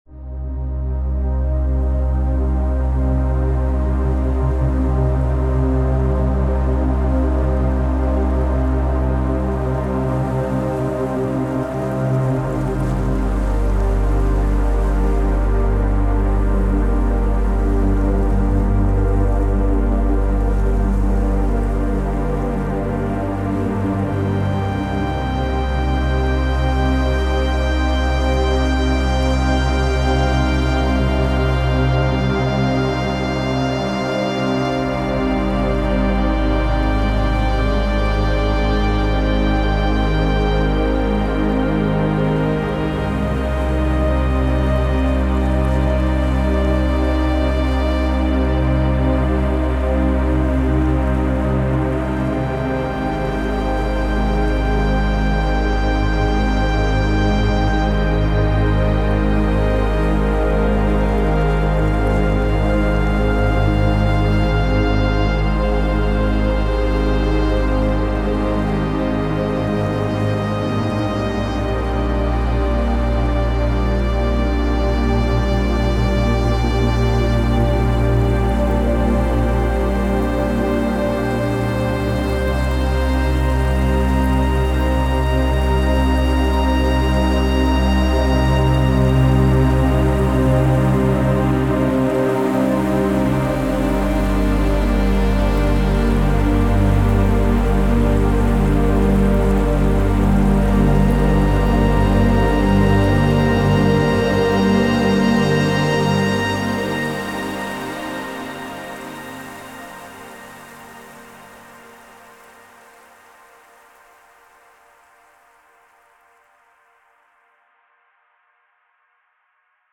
Ambient Cinematic / FX Psy-Trance Techno Trance